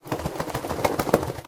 latest / assets / minecraft / sounds / mob / wolf / shake.ogg
shake.ogg